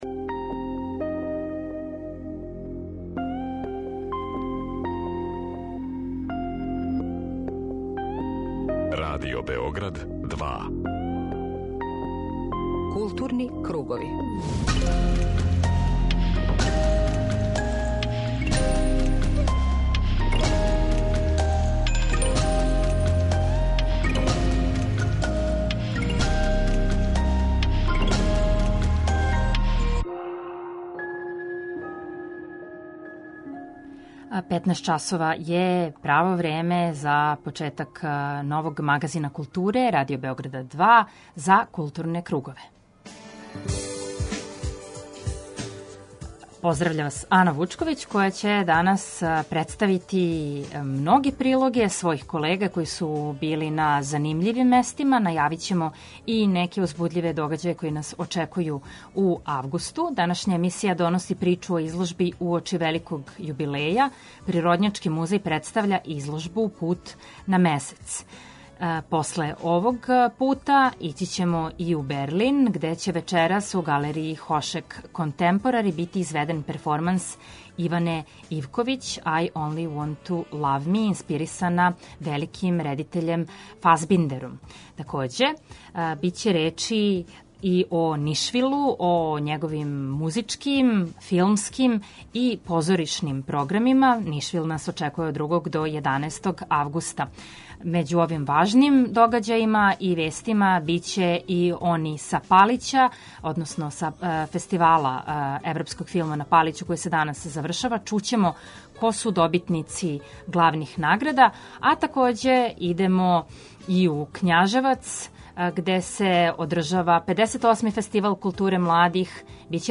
Магазин културе Радио Београда 2